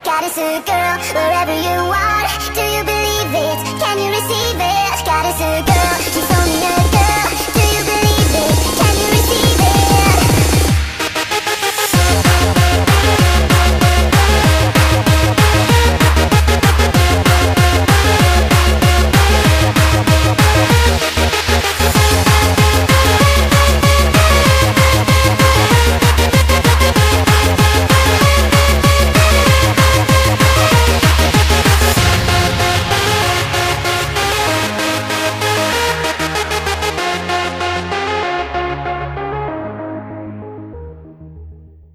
goal_anthem_short.mp3